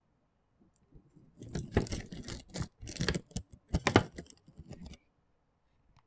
• kitchen table noises crashes neural.wav
Immerse yourself in the chaotic symphony of kitchen table noises and crashes, processed through a neural network.
kitchen_table_noises_crashes_neural_ux1.wav